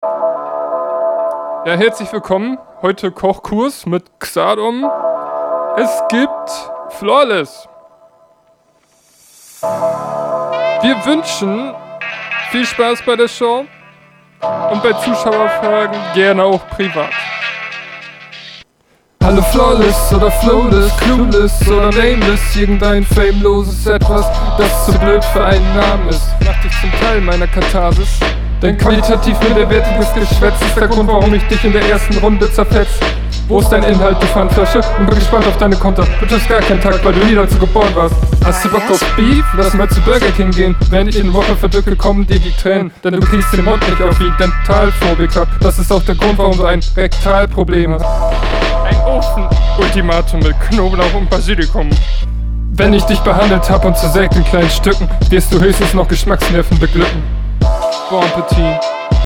Jo Intro ist meh.
Flow sehr, sehr gewöhnungsbedürftig.